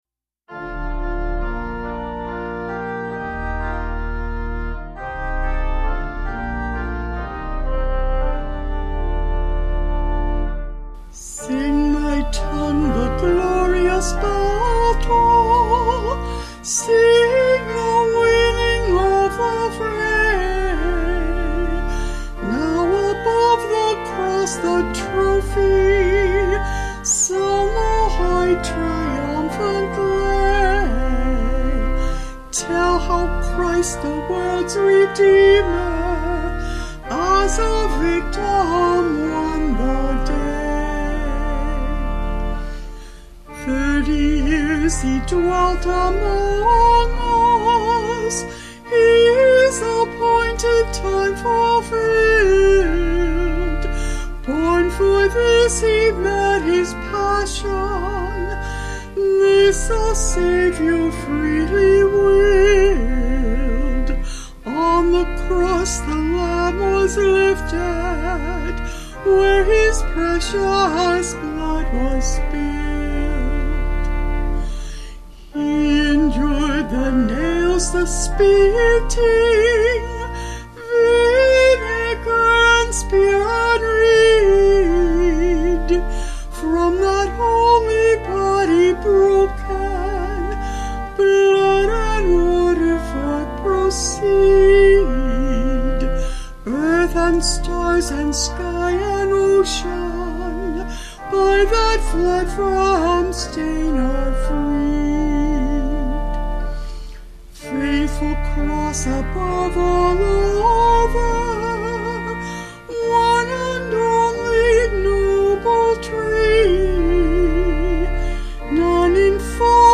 Vocals and Organ   300kb Sung Lyrics